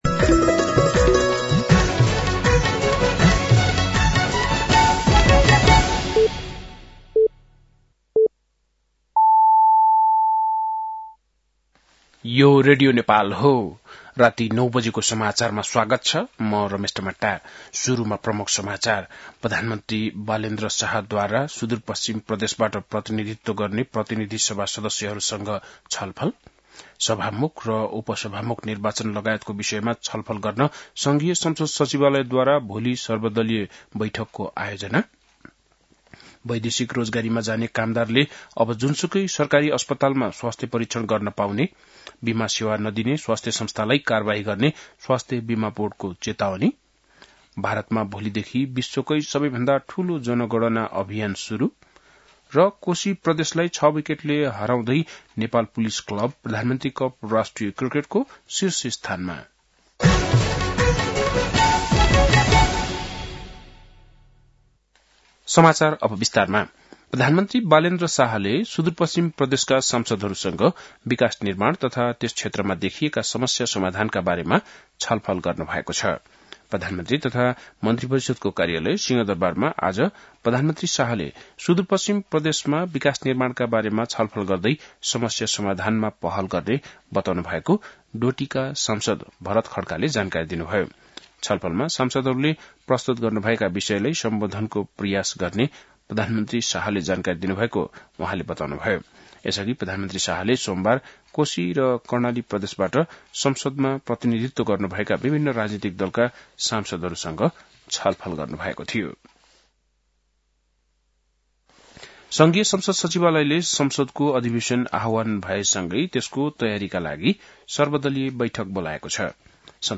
बेलुकी ९ बजेको नेपाली समाचार : १७ चैत , २०८२